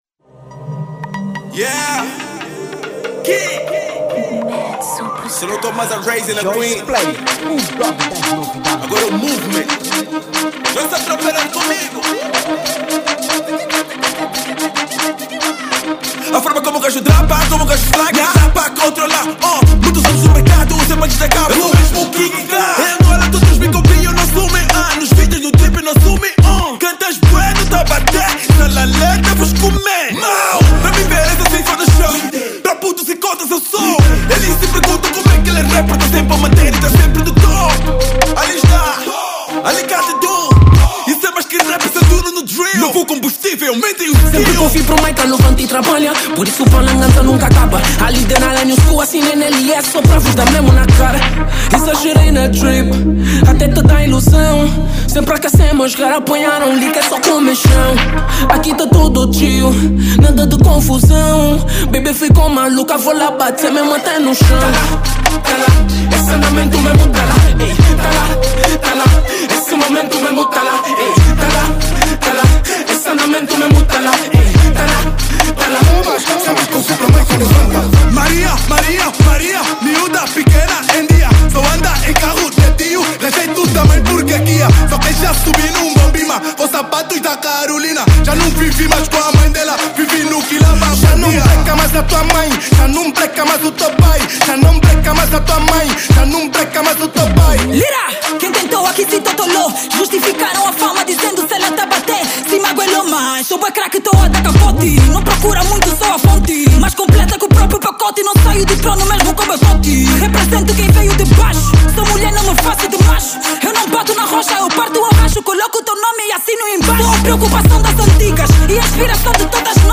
Género: Rap / Drill